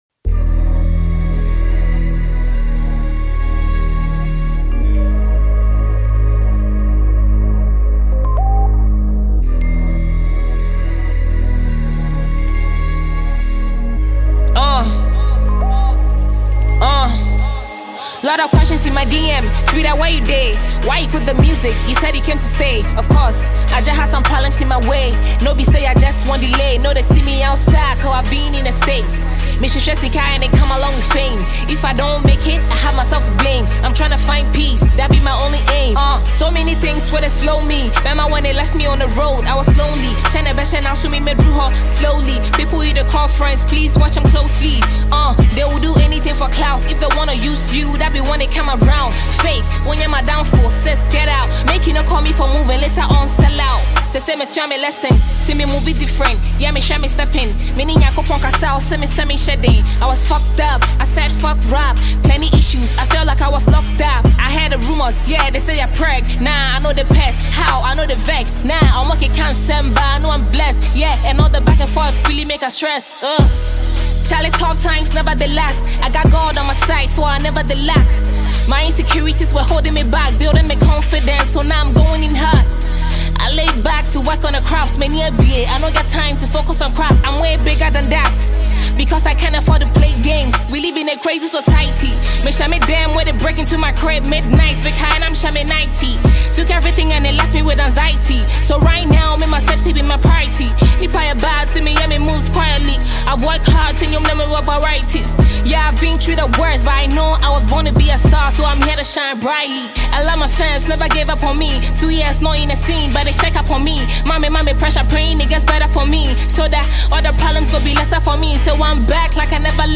Ghanaian female rapper